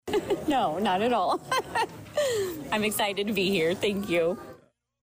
At the conclusion of Thursday afternoon’s Investiture in room 4B of the Vermilion County Courthouse; Judge Starwalt stated that, no; she could not possibly have imagined things turning out this way back when she wanted to go into law enforcement.